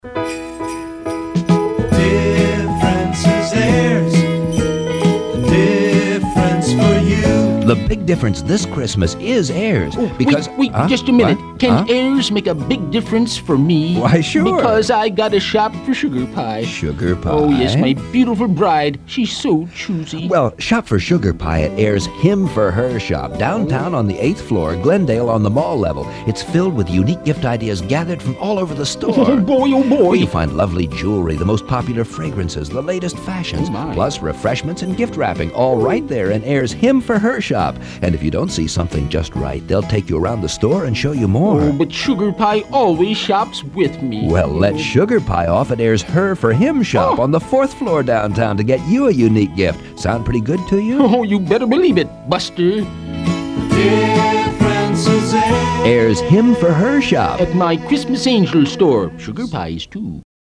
This was my tribute to old-time radio actor Bill Thompson, who played henpecked husband Wallace Wimple on “Fibber McGee and Molly.” He used the same voice as Mr. Smee in Walt Disney’s “Peter Pan,” and as Droopy Dog. I thought I did a pretty good version for the large Midwest chain L.S. Ayres (“Your Christmas Angel Store”).